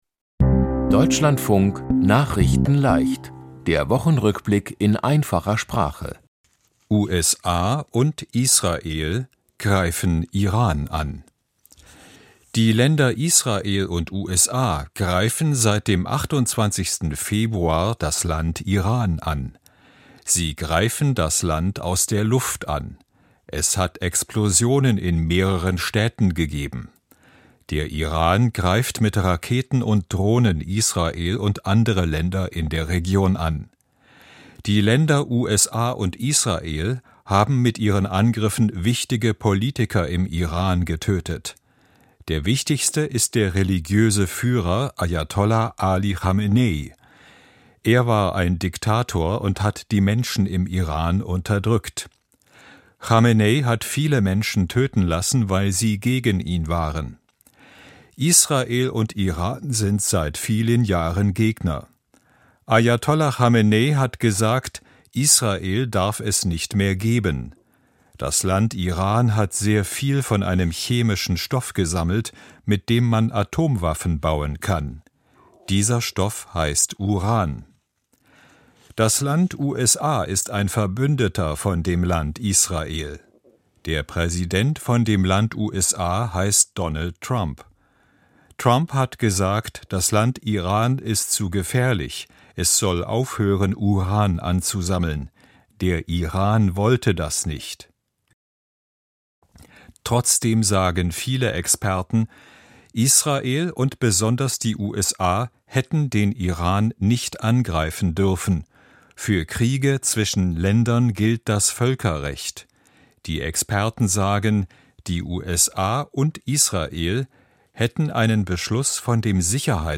Die Themen diese Woche: USA und Israel greifen Iran an, Viele Deutsche sitzen im Nahen Osten fest, Strengere Regeln beim Bürger-Geld und Deutsche Sportler nehmen nicht an Eröffnung der Paralympics teil. nachrichtenleicht - der Wochenrückblick in einfacher Sprache.